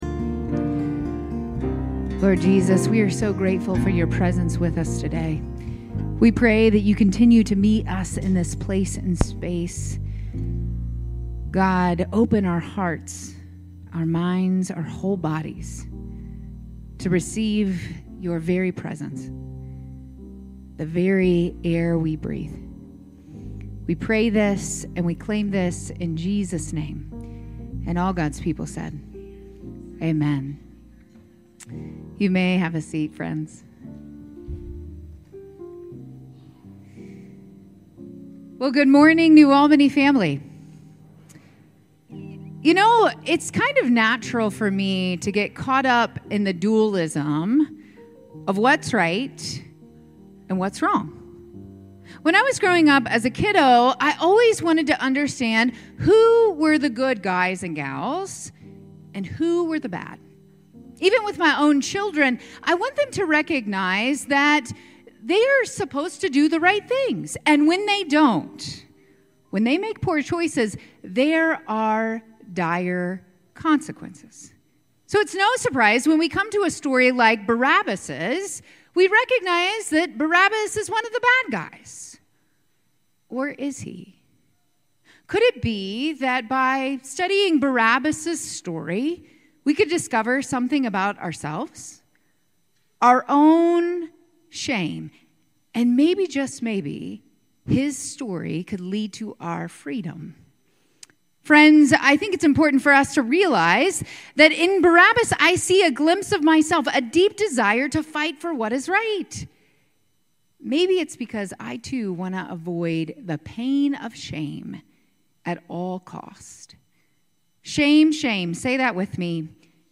9:30 AM Contemporary Worship Service 03/23/25